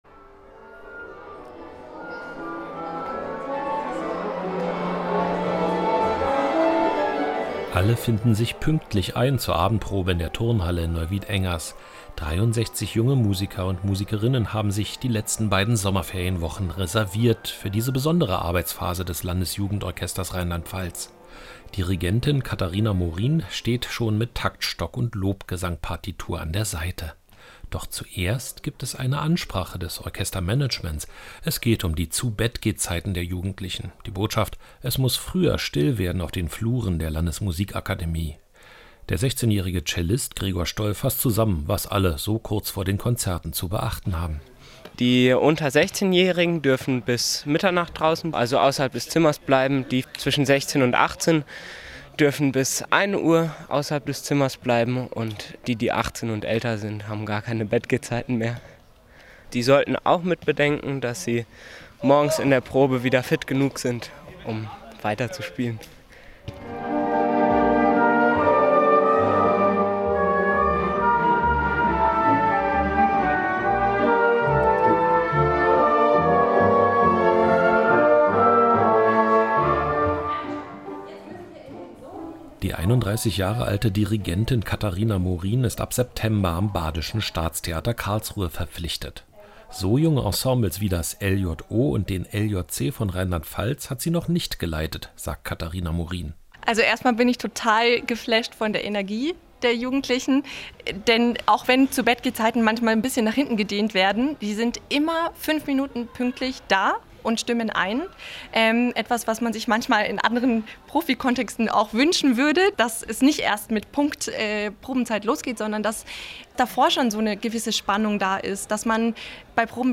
Landesjugendchor und -orchester in Rheinland-Pfalz proben „Lobgesang“